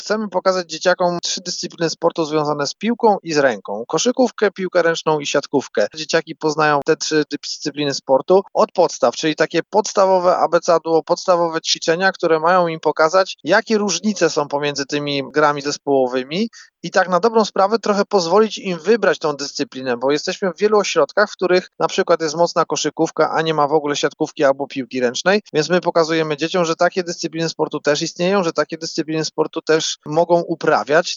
Pokazujemy dzieciom, że takie dyscypliny sportu też istnieją i takie dyscypliny sportu też mogą uprawiać – wyjaśnia Krzysztof Ignaczak, mistrz świata w siatkówce, jeden z trenerów i pomysłodawców projektu